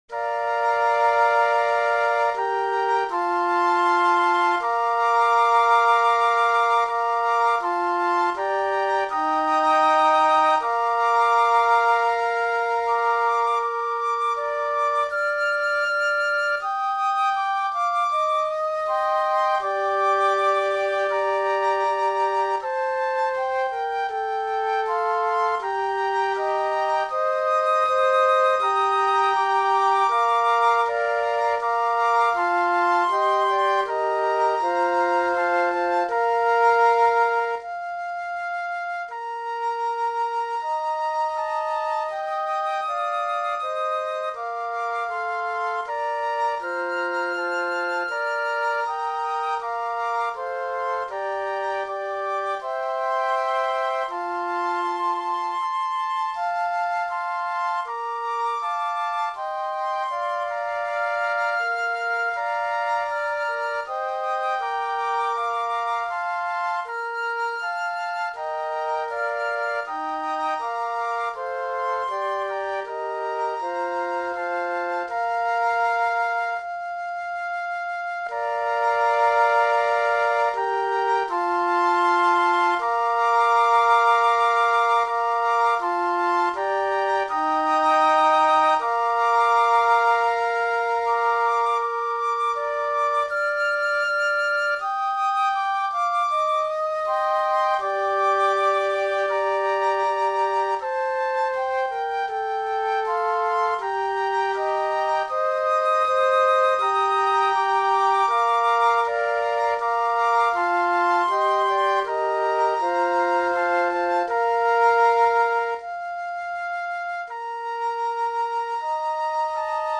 Voicing: Flute Quartet